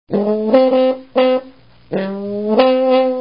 But in our examples we use samples with low quality because they are more fast in downloading.
Let's go further. Take two samples of trumpet -